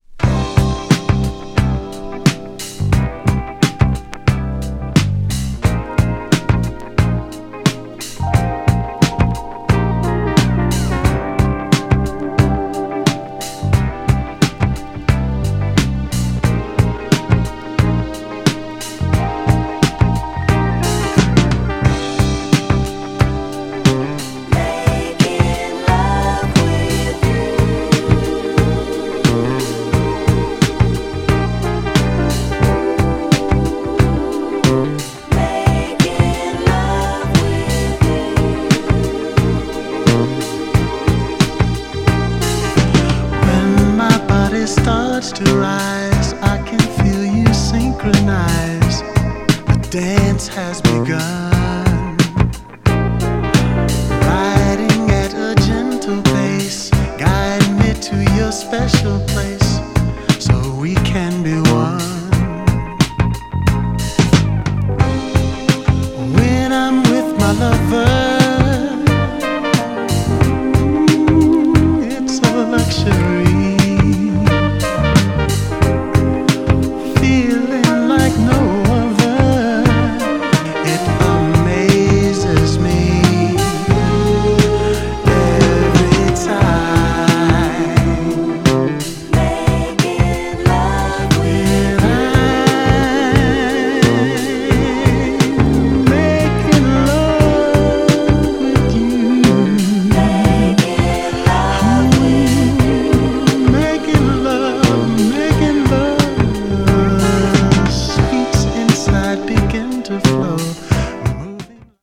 GENRE Dance Classic
BPM 121〜125BPM
GARAGE_CLASSIC # GROOVY
JAZZY # アップリフティング